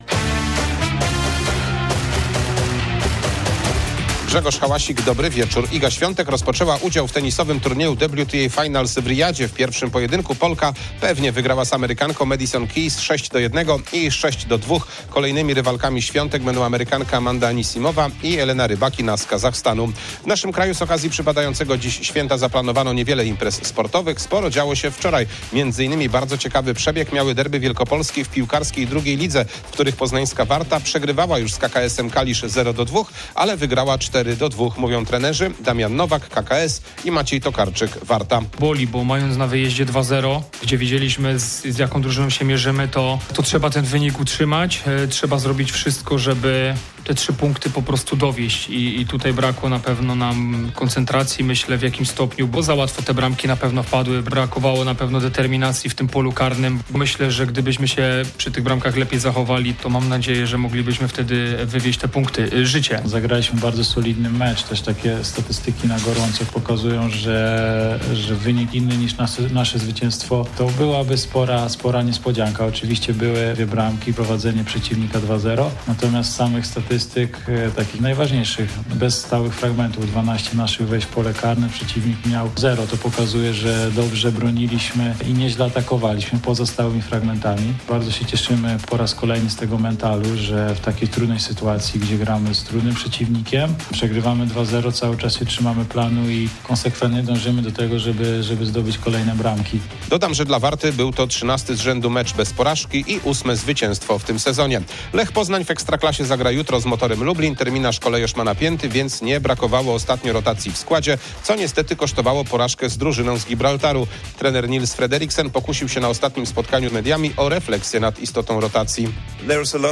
01.11.2025 SERWIS SPORTOWY GODZ. 19:05